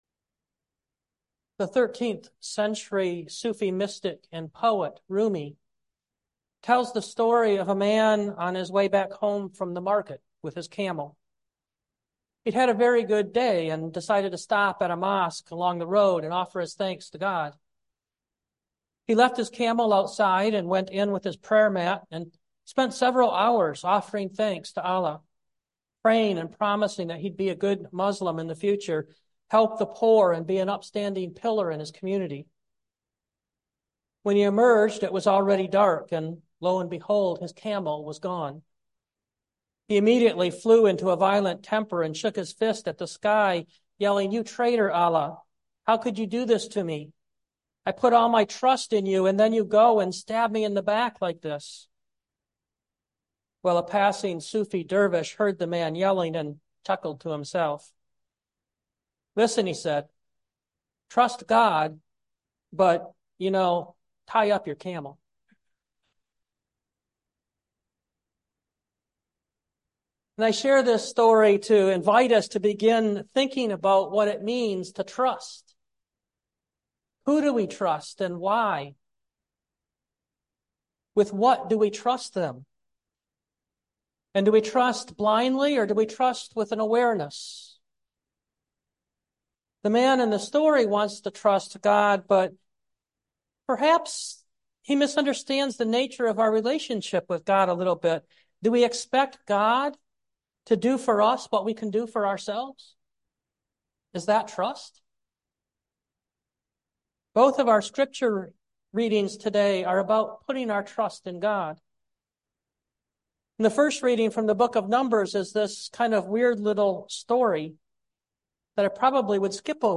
2024 In The Light Preacher